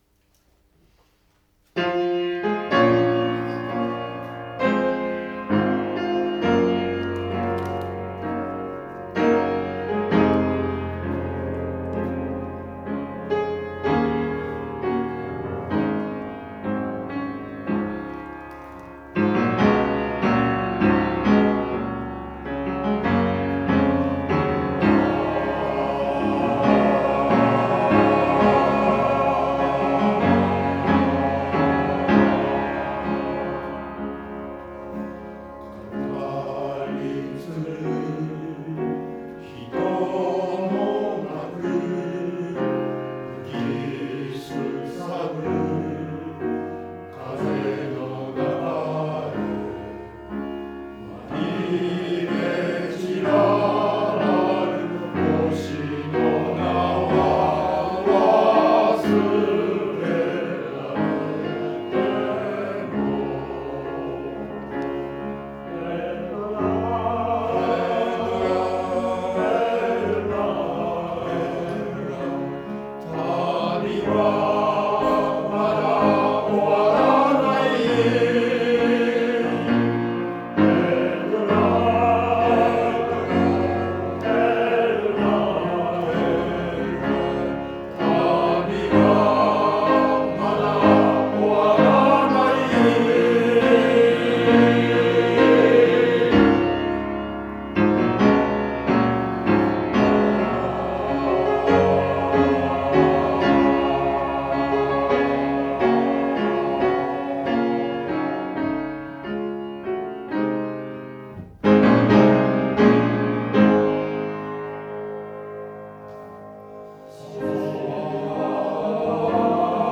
合唱祭が近づく、東小での強化練習
合唱祭が近づき今回は強化練習で、合唱祭で歌う２曲を、時間をかけて練習しました。
四分音符で終わる時のクレッシェンド、２分音符を十分に伸ばして、正確に切る・・・